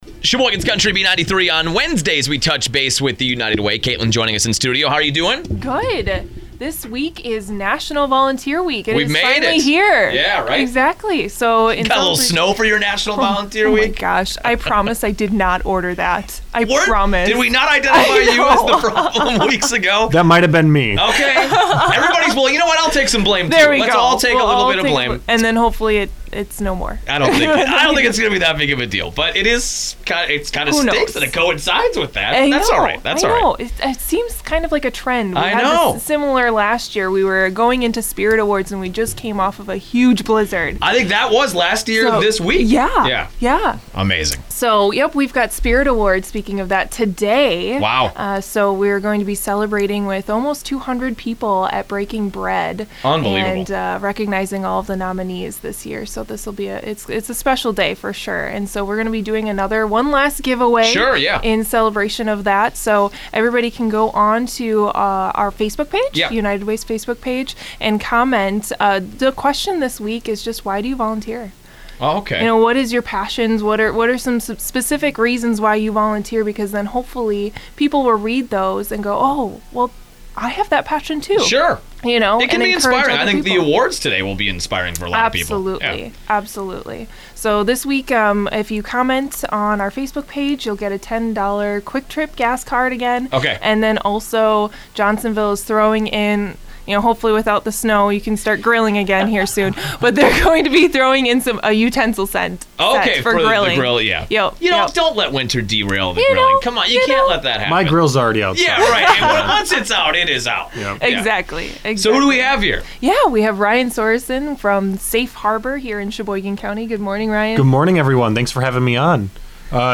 Safe Harbor- Radio Spot